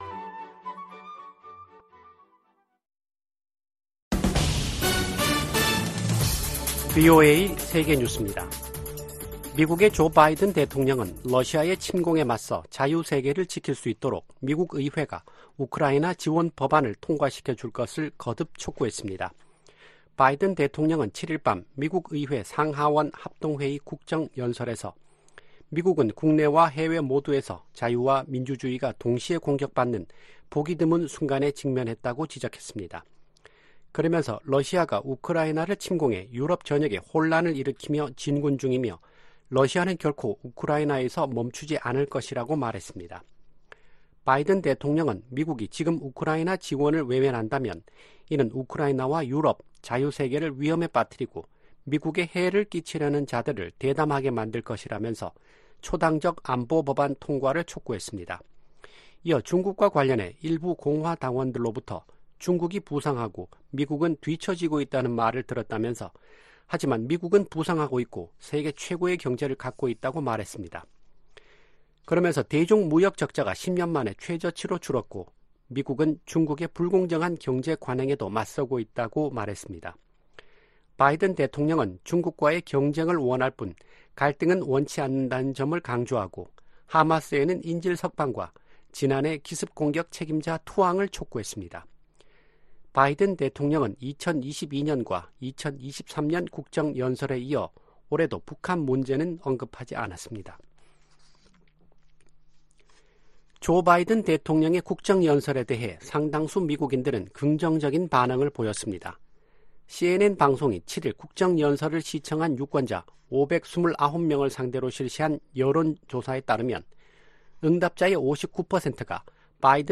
VOA 한국어 아침 뉴스 프로그램 '워싱턴 뉴스 광장' 2024년 3월 9일 방송입니다. 조 바이든 미국 대통령이 국정연설에서 자유 세계를 지키기 위해 우크라이나를 침공한 러시아를 막아야 한다고 강조했습니다. '프리덤실드' 미한 연합훈련이 진행 중인 가운데 김정은 북한 국무위원장이 서울 겨냥 포사격 훈련을 지도했습니다. 인도태평양 국가들은 북한이 계속하고 있는 여러 행동들을 깊이 우려하고 있다고 대니얼 크리튼브링크 미 국무부 동아태 차관보가 밝혔습니다.